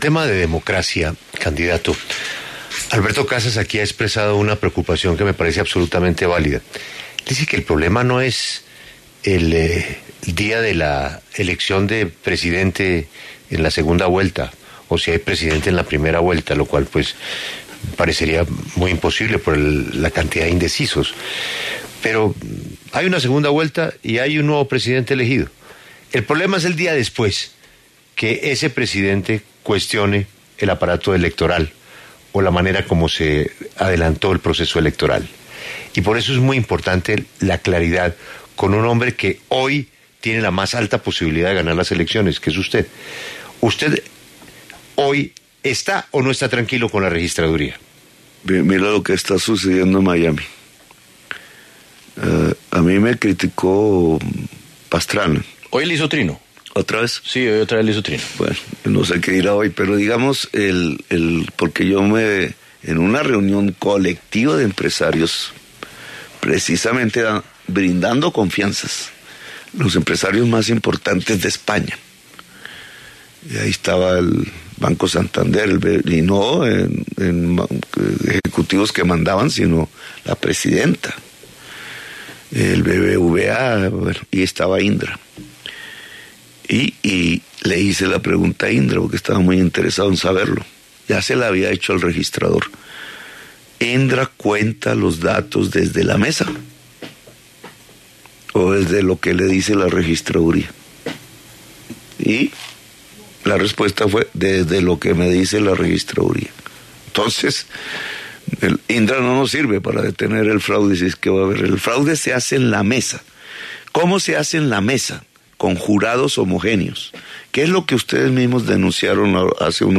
En entrevista con La W, el candidato y líder de la Colombia Humana, ratificó que no confía en la Registraduría.
Gustavo Petro, precandidato del Pacto Histórico, habla en La W